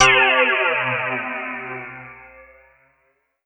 Phlange_C4.wav